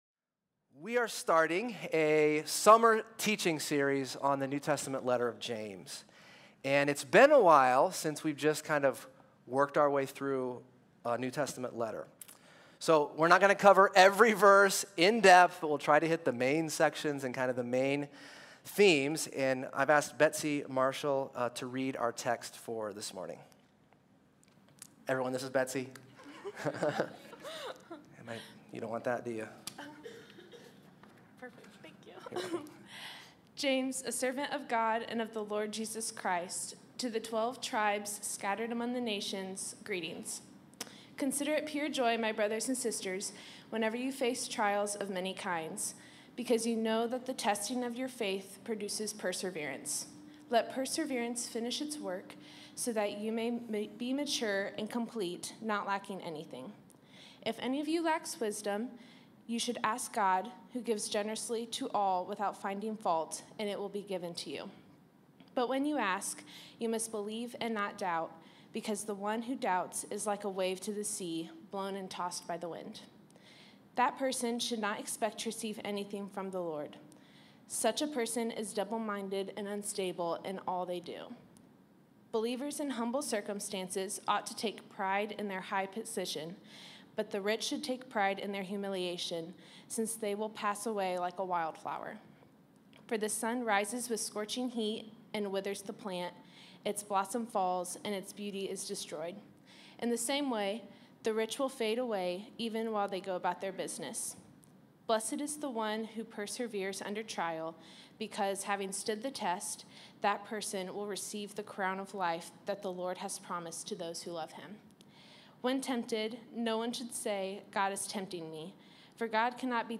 A message from the series "James."